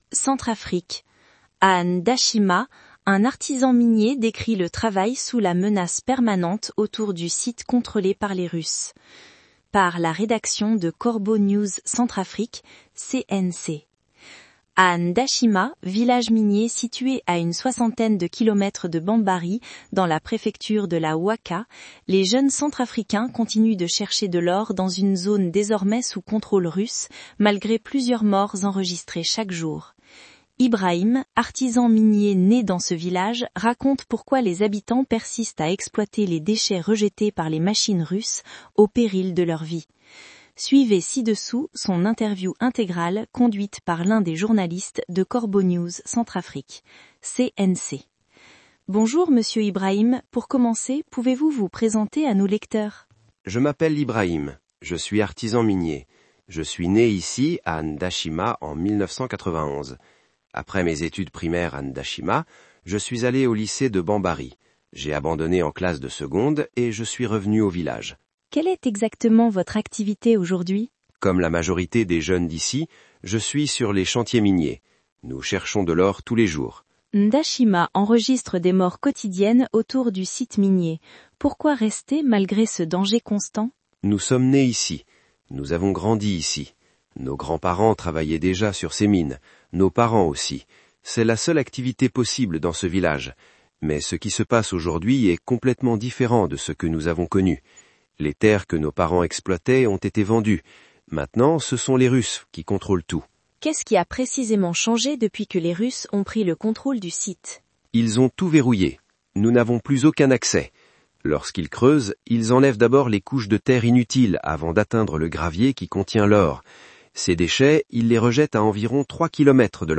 Suivez ci-dessous son interview intégrale conduite par l’un des journalistes de Corbeau News Centrafrique (CNC).